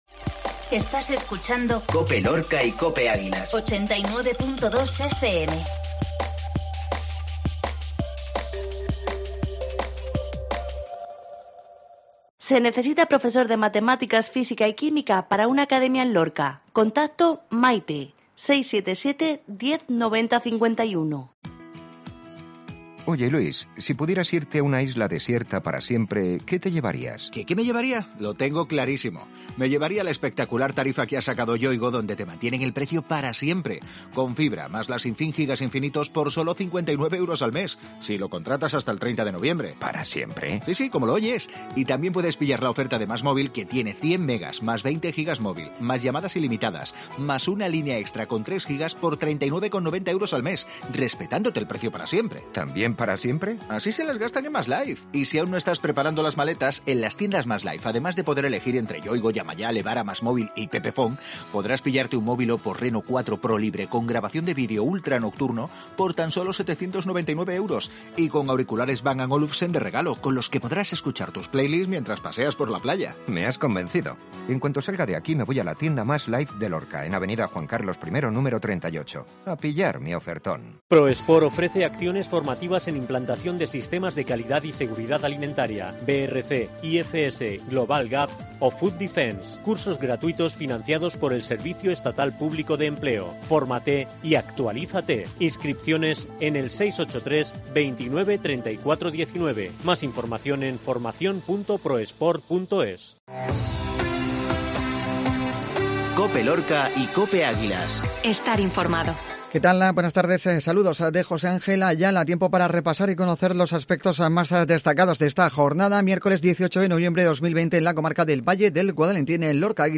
INFORMATIVO MEDIODÍA COPE LORCA MIÉRCOLES